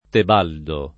Tebaldo [ teb # ldo ] → Tibaldo